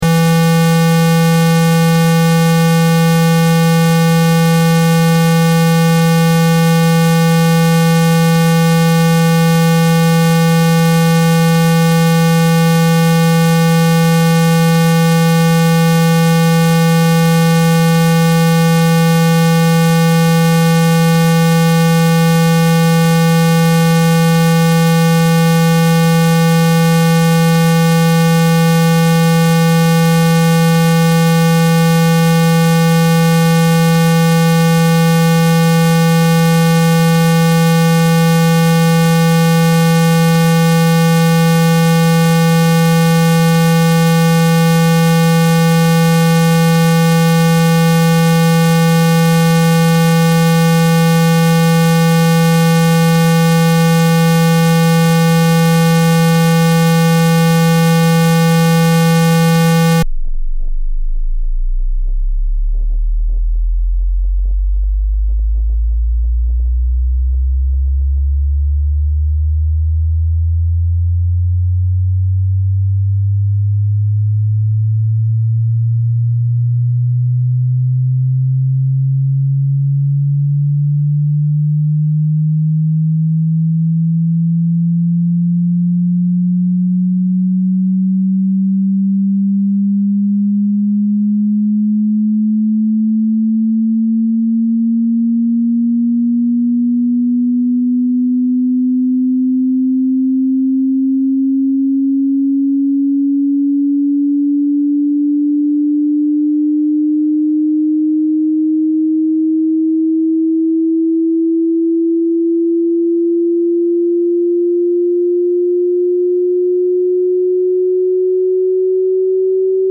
🔊 Handy Lautsprecher reinigen mit sound effects free download